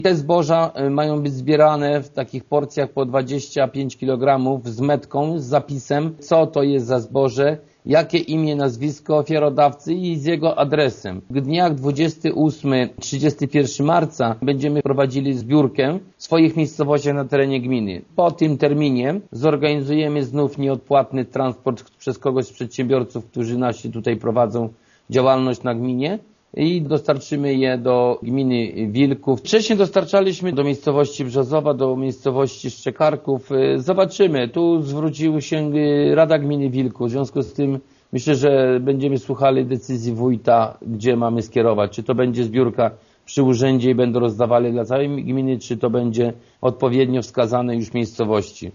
O szczegółach zbiórki informuje wójt Jerzy Kędra: